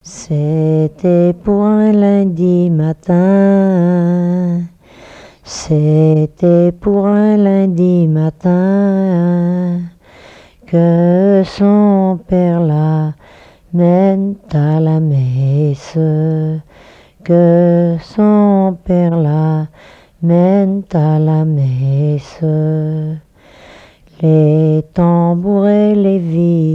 collecte en Vendée
répertoire de chansons de noces
Pièce musicale inédite